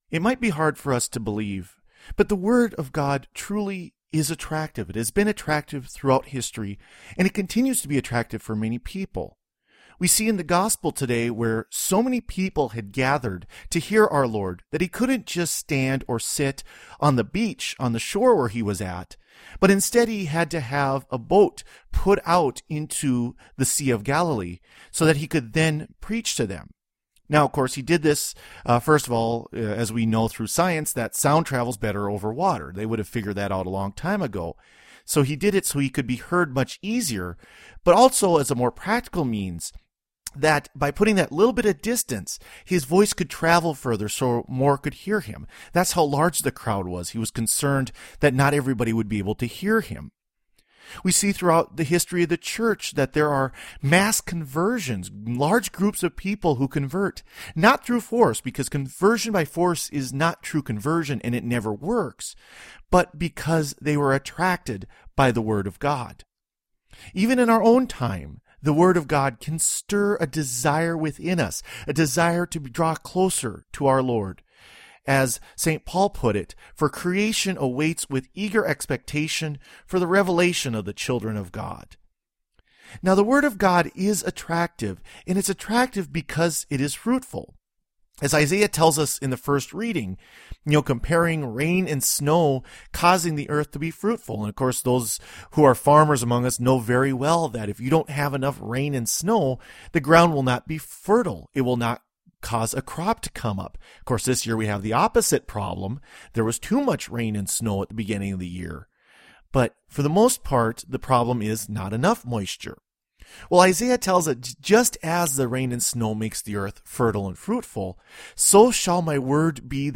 Homily for the Fifteenth Sunday in Ordinary Time